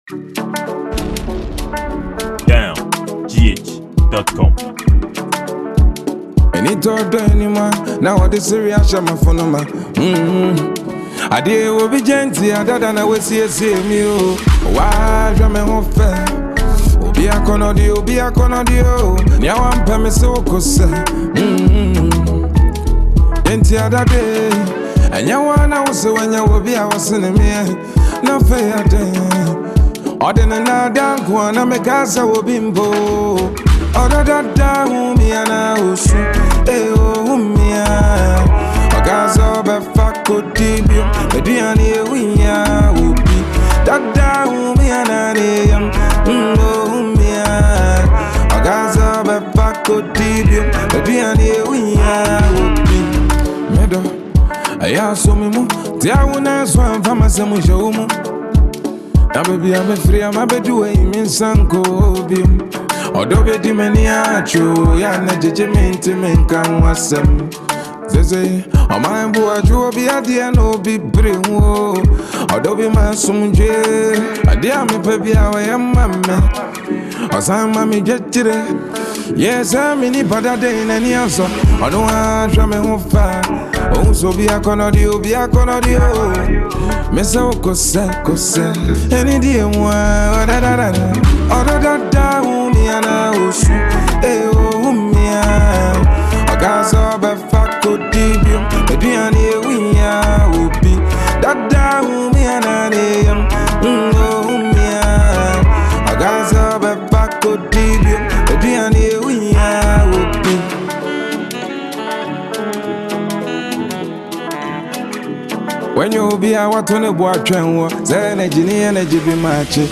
Highlife Ghanaian musician